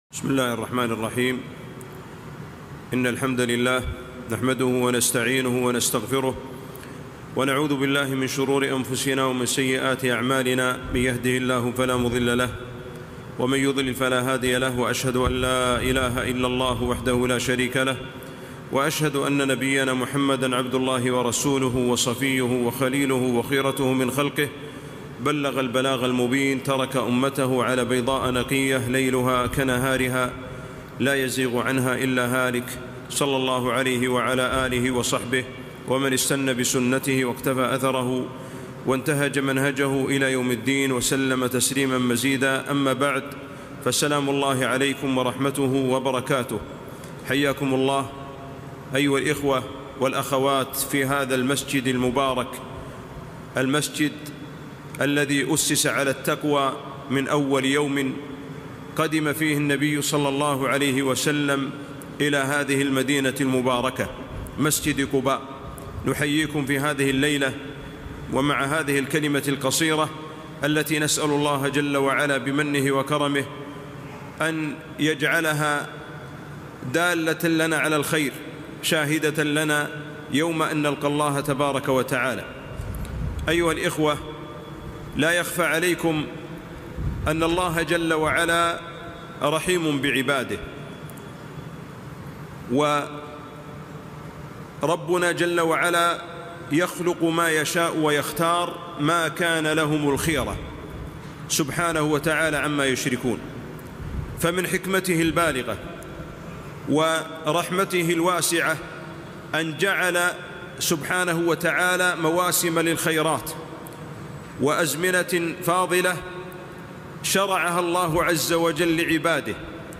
محاضرة - ﴿وَلَيالٍ عَشرٍ﴾.